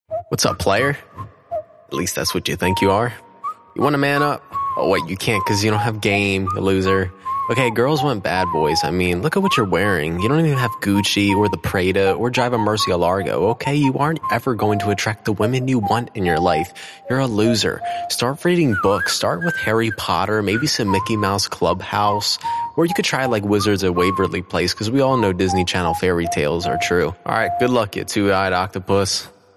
Una pista de crítica o burla, parte 2 de una serie.